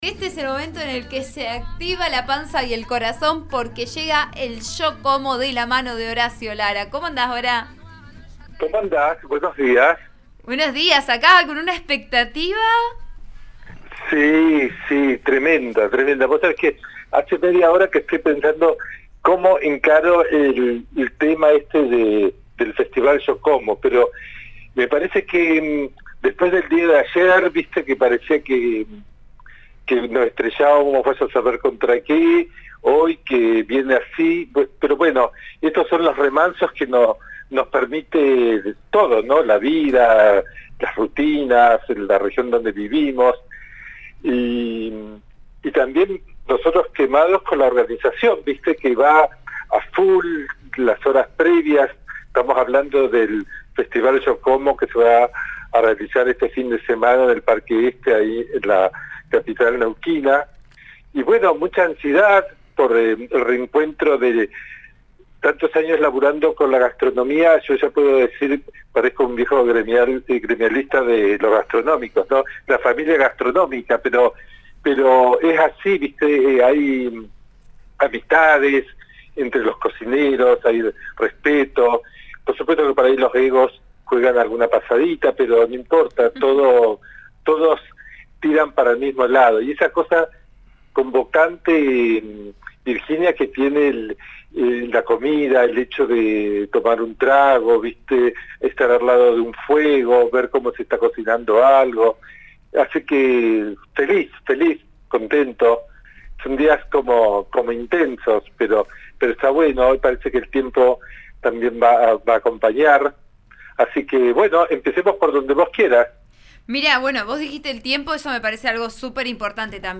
en RN RADIO: